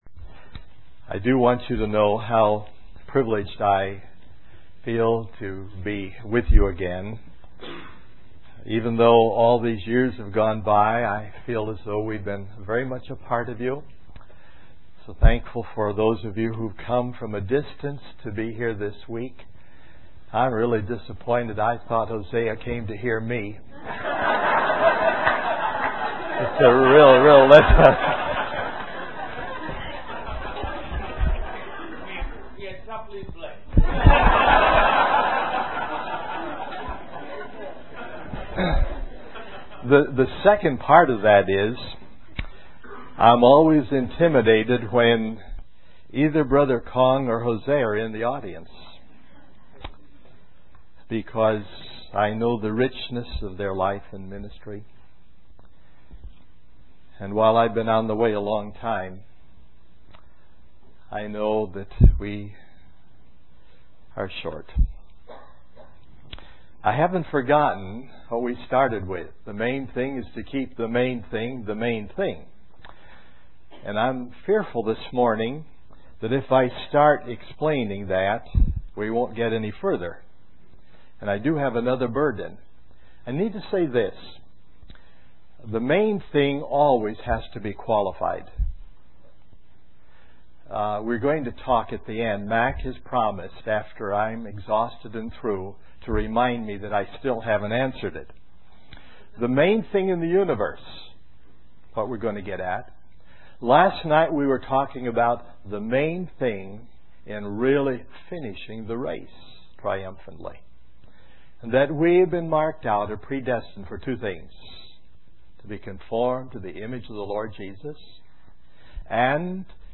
In this sermon, the speaker emphasizes the importance of using our time and possessions to serve others. He shares a story of a woman who bartered her strength, time, and groceries to help her neighbor, highlighting the concept of bartering in our daily lives. The speaker then discusses different judgments mentioned in the Bible, explaining that as believers in Jesus, we are saved from the judgment of the great white throne.